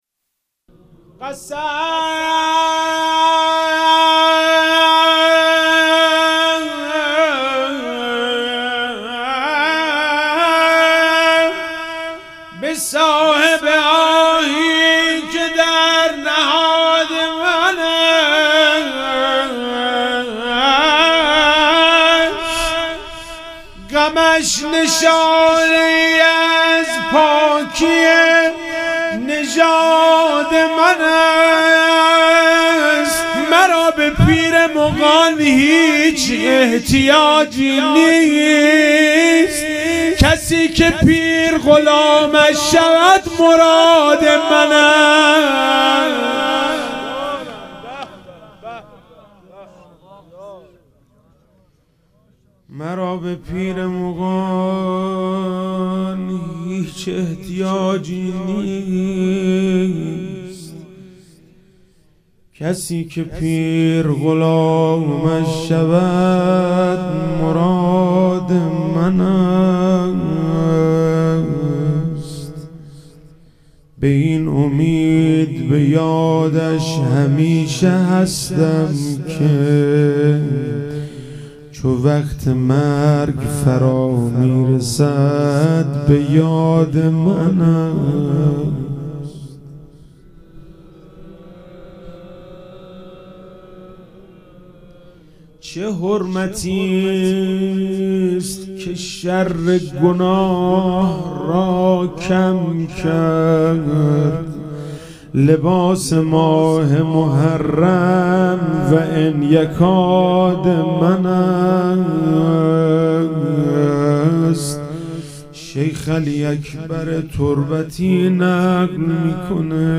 محرم99 - شب چهارم - روضه - قسم به صاحب آهی که در نهاد من است